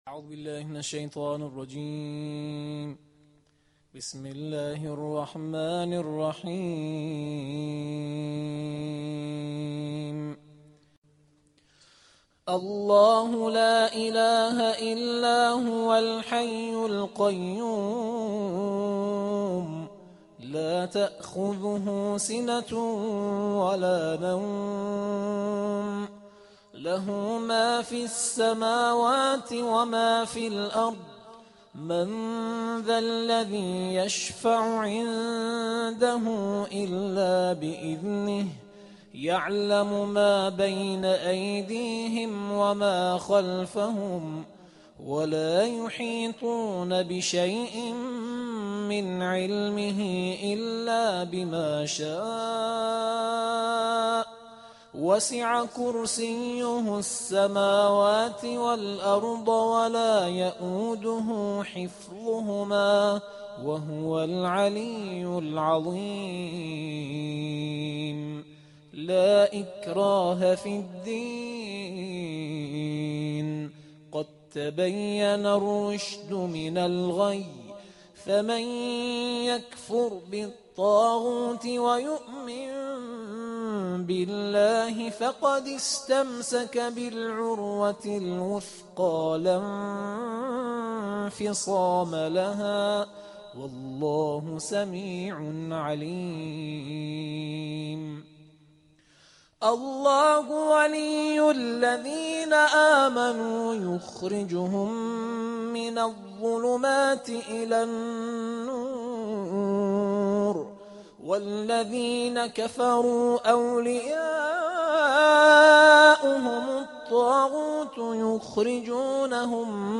آیت‌الکرسی را قرائت کرد